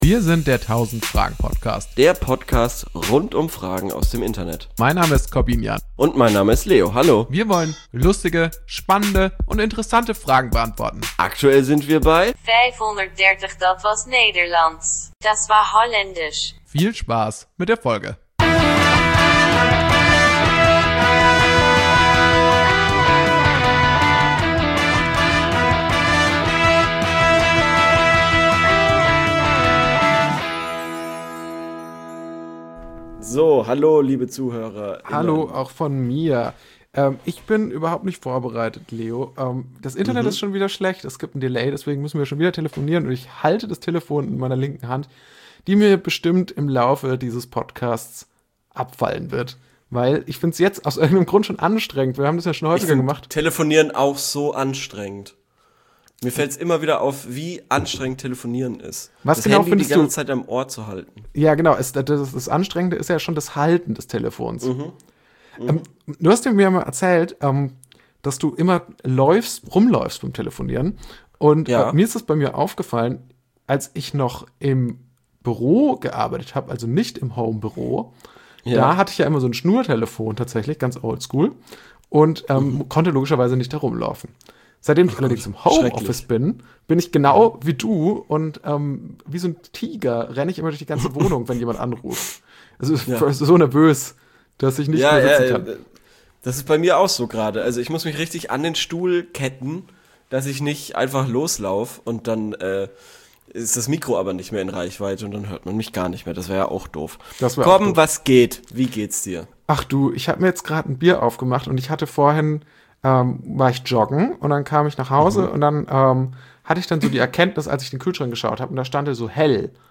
Ich hab versucht zu retten, was zu retten war - sorry für die Tonprobleme though...